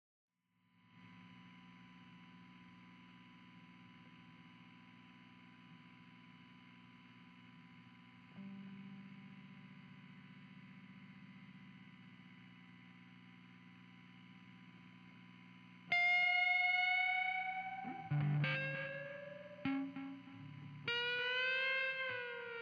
Dirty Power/Amp Noise
I recently moved to a new house and now my guitar amps make extra noise. It doesn't sound like ground loop hum. This is a Strat w/single coils running through a Fender Hot Rod.